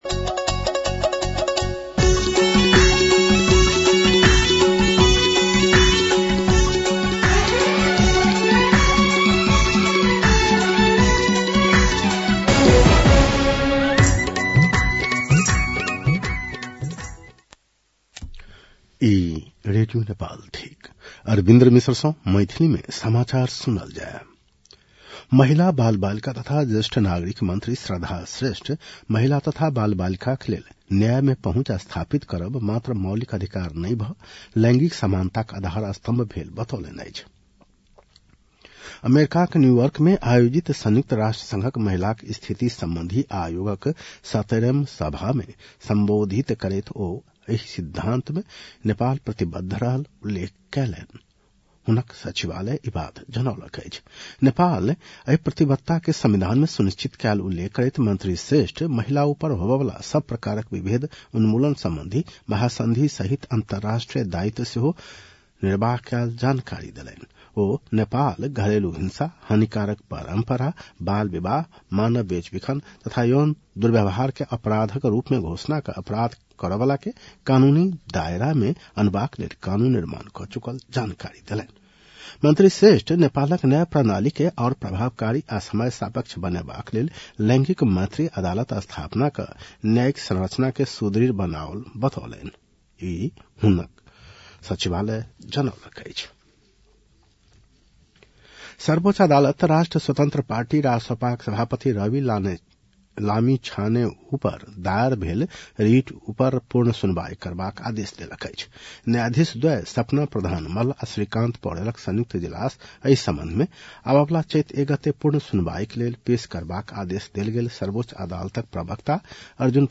मैथिली भाषामा समाचार : २७ फागुन , २०८२